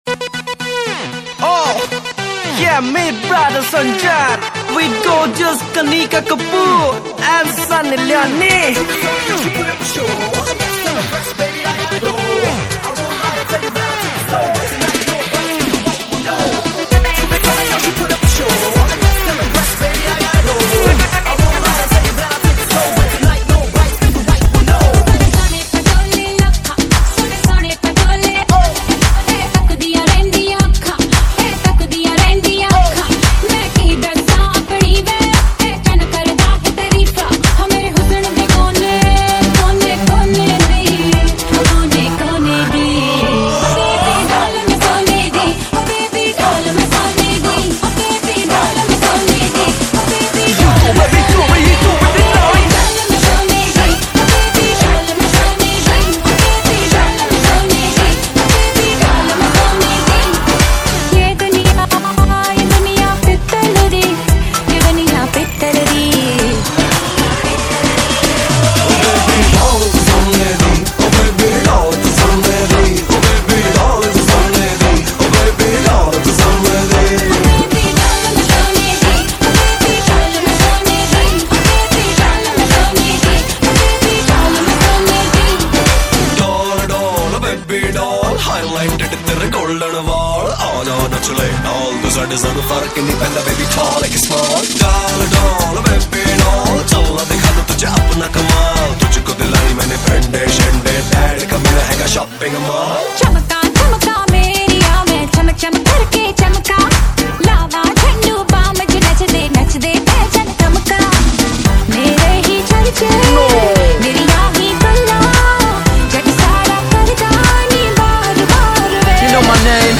Hindi Dj Remix songs